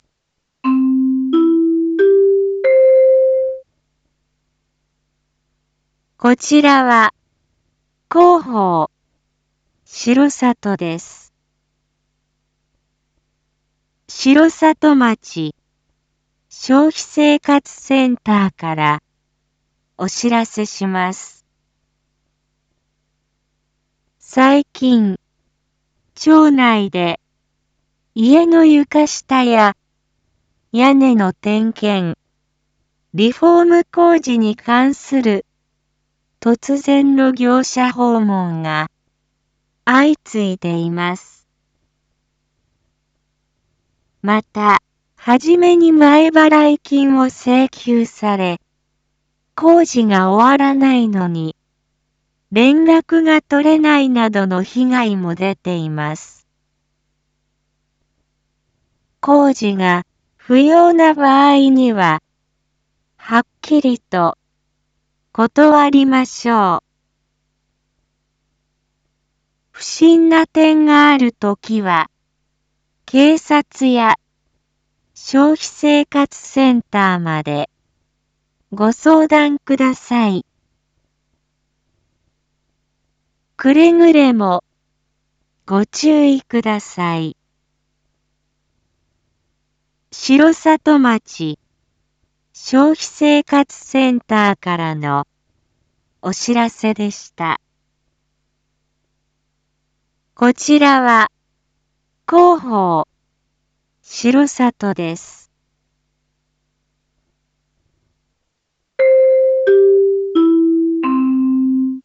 一般放送情報
Back Home 一般放送情報 音声放送 再生 一般放送情報 登録日時：2024-12-23 19:01:53 タイトル：町消費生活センター② インフォメーション：こちらは、広報しろさとです。